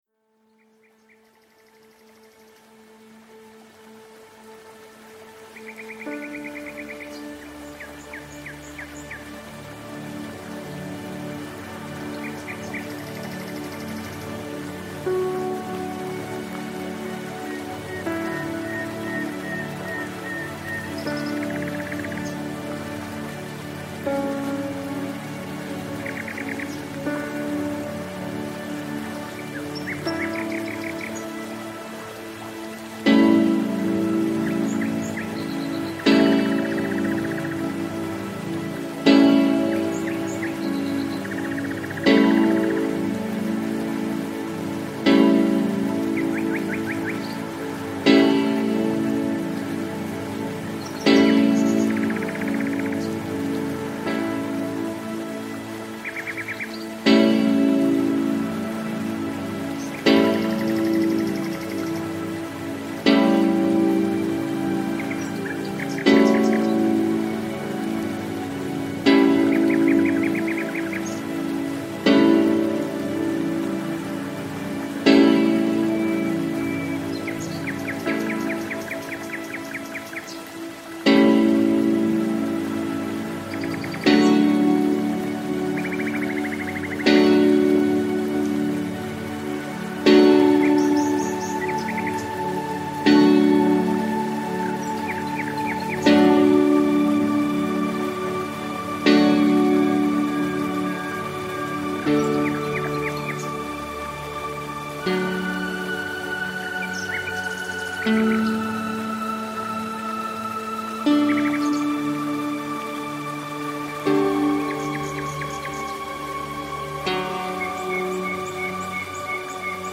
Звуки для собак
Возбуждённый пёс громко лает